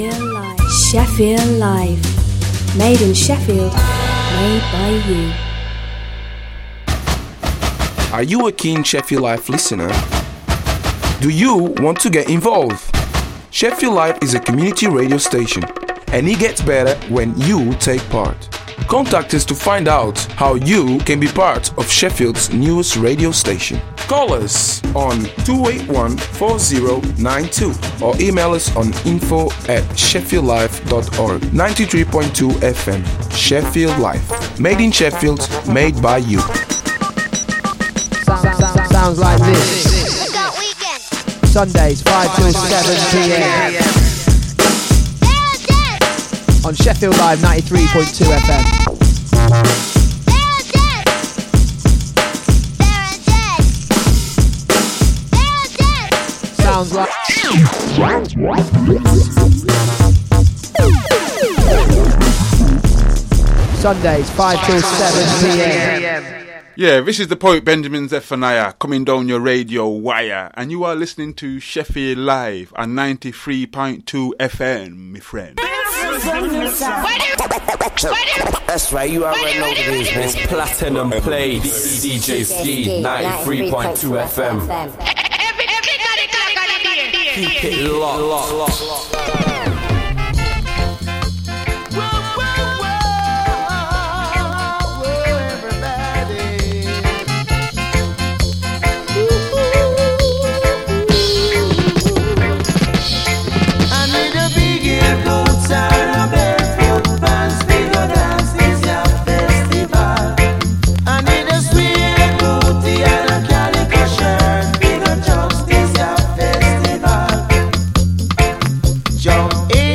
Platinum Plates will re-introduce you to tracks and artist profiles/updates and will re-ignite the light for all the revival Reggae, Rockers, Conscious lyrics, Lovers, Ska and Version fanatics out there.